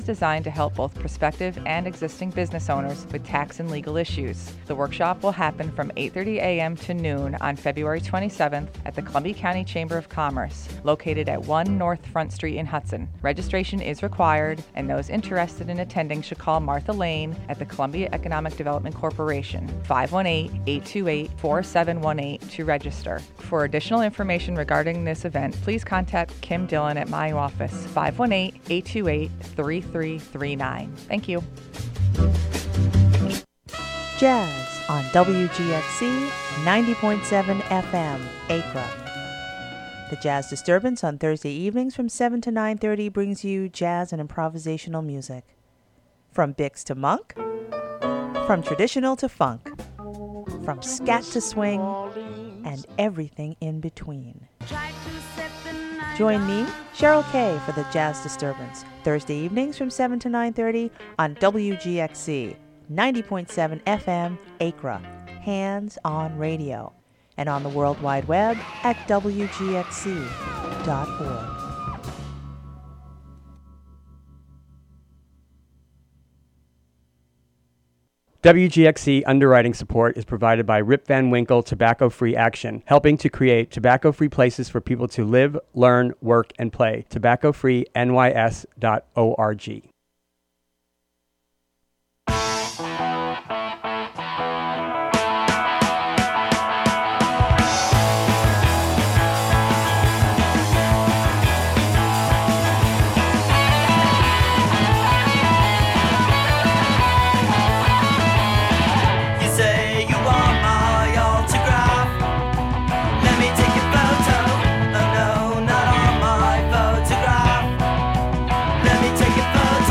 The final broadcast of Blind Spot Radio. This month's show focuses on the photography show: On Time and Place, Is an exhibition Celebrating Scenic Hudson’s First 50 Years in existence.
bsr010-live.mp3